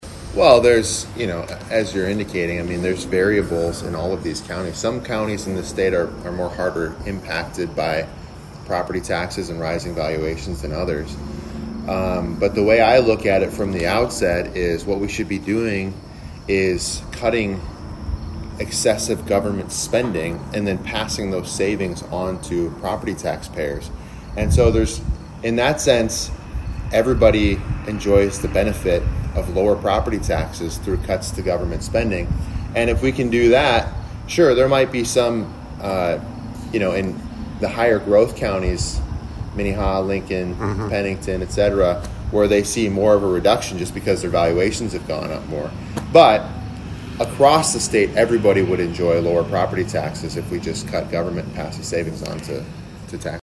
HubCityRadio had an opportunity to do an interview with him to address several different topics.